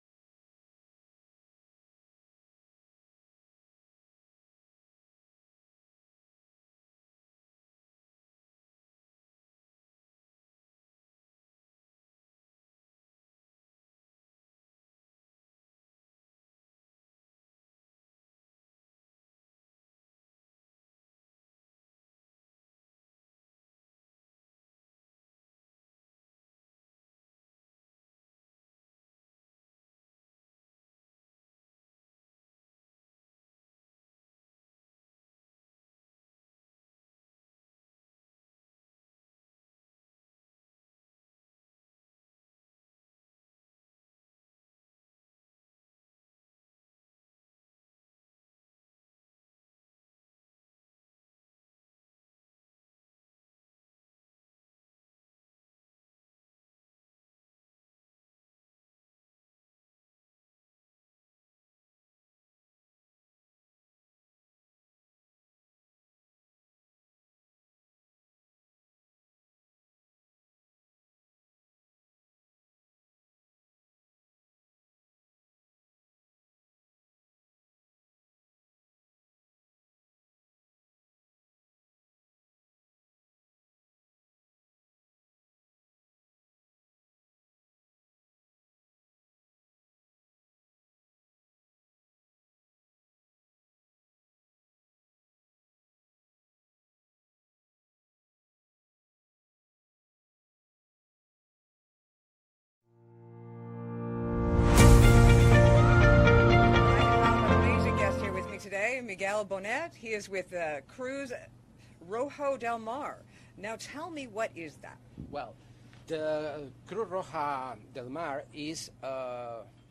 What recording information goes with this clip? If you missed some of the great interviews that were had during The Balearic Yacht Show, not to worry, we will be bringing you a few of them here!